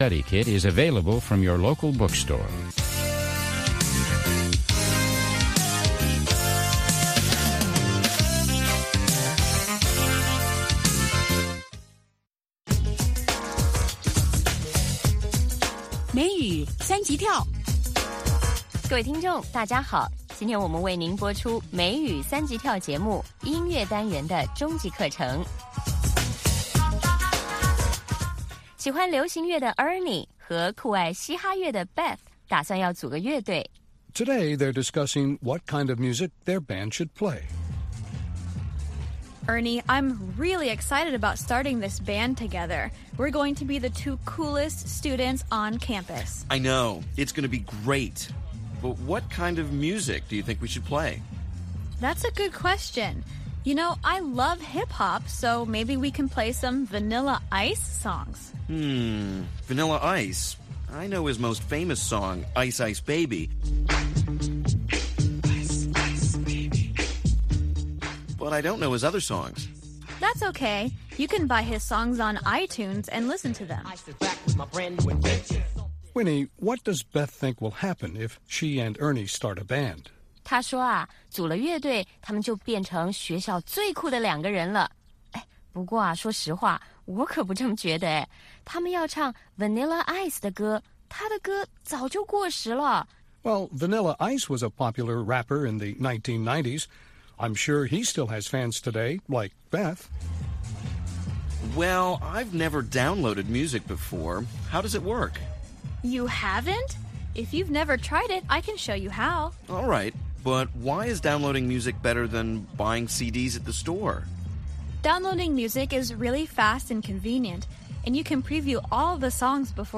北京时间下午5-6点广播节目。广播内容包括收听英语以及《时事大家谈》(重播)